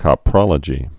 (kŏ-prŏlə-jē)